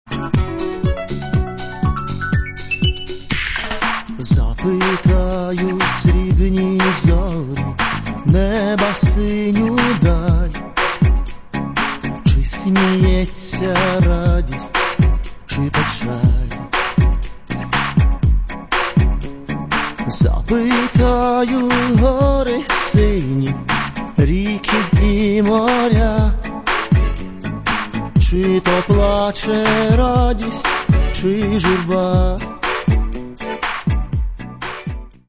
Каталог -> Естрада -> Співаки